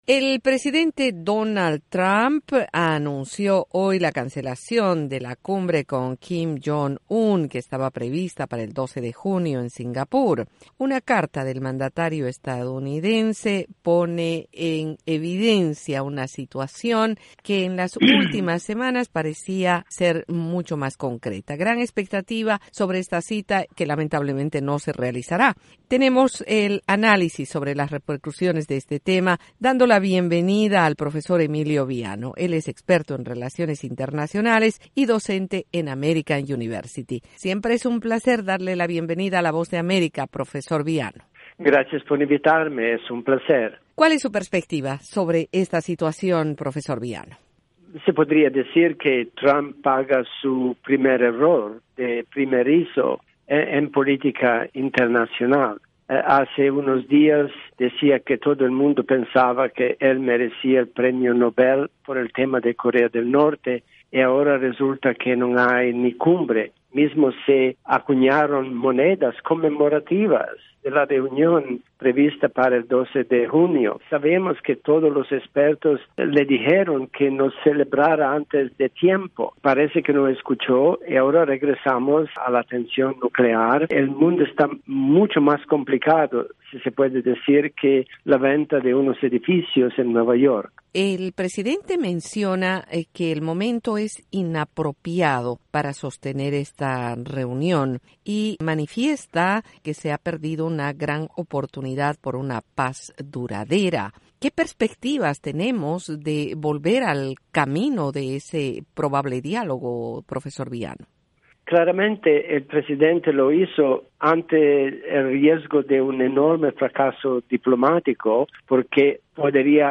Colaboración con la entrevista